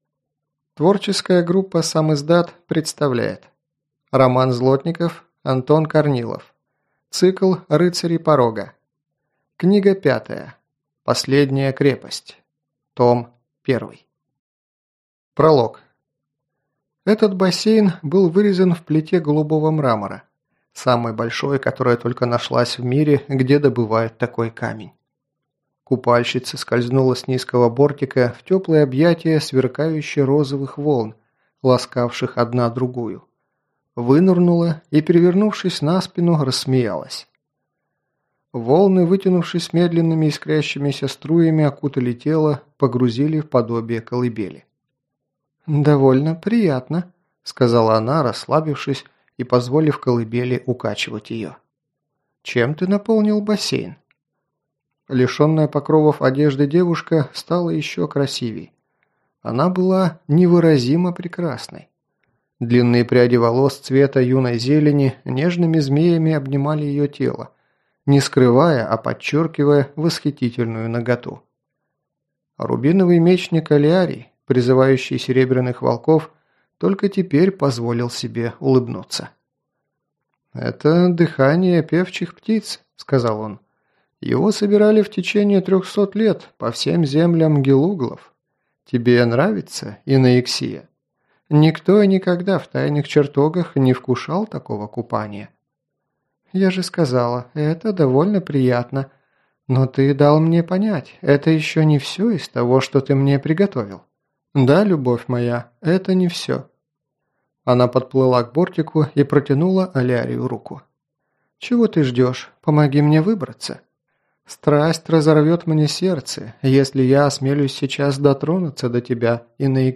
Аудиокнига Последняя крепость. Том 1 | Библиотека аудиокниг